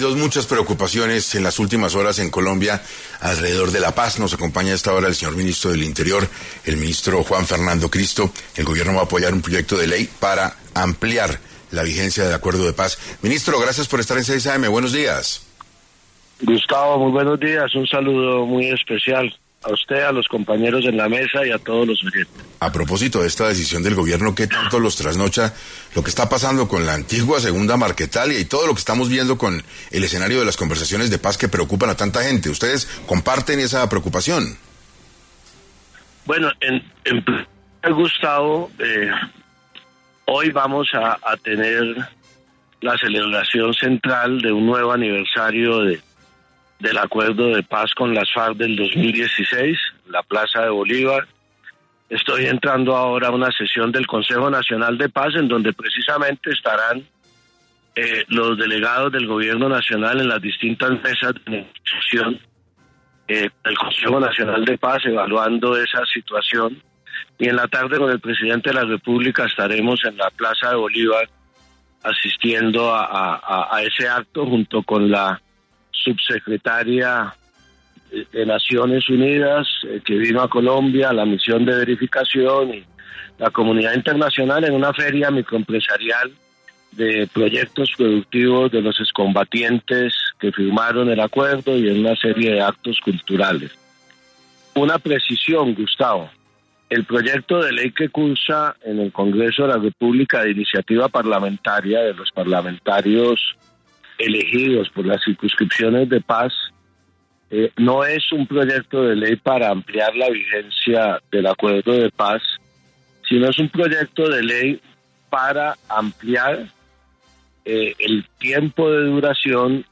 Juan Fernando Cristo, ministro del Interior, habló sobre por qué el Gobierno considera ampliar la vigencia del Acuerdo de Paz y de la situación de la fragmentación de disidencias de las FARC